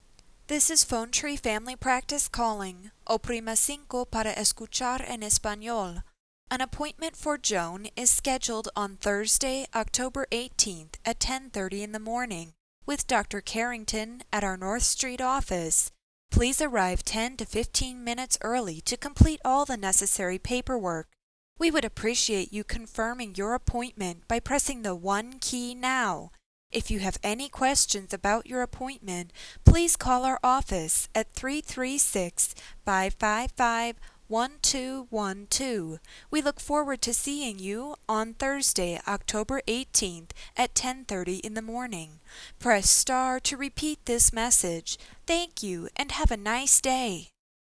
The 3500 with its superb text-to-speech quality is without-a-doubt the Mercedes of our PhoneTree line.
AppointmentReminder_English.wav